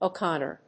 /oˈkɑnɝ(米国英語), əʊˈkɑ:nɜ:(英国英語)/